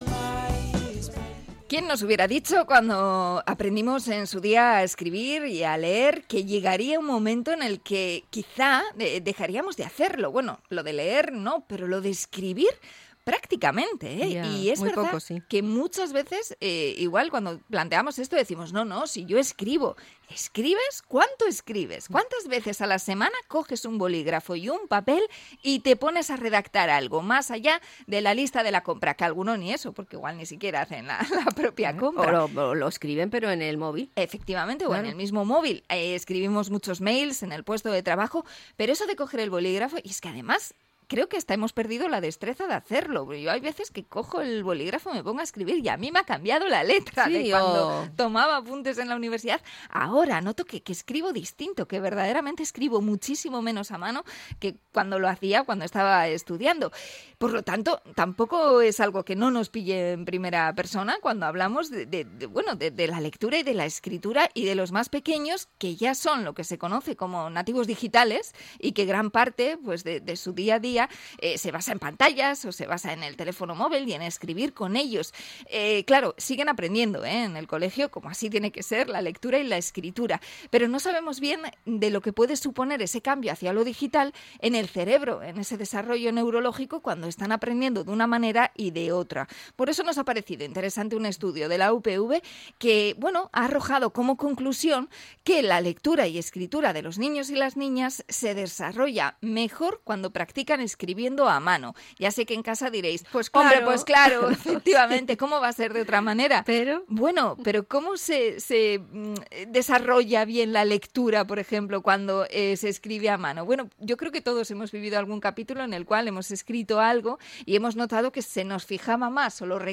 Entrevista a investigadora sobre la escritura a mano